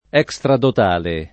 vai all'elenco alfabetico delle voci ingrandisci il carattere 100% rimpicciolisci il carattere stampa invia tramite posta elettronica codividi su Facebook extradotale [ H k S tradot # le ] o estradotale (pop. stradotale ) agg.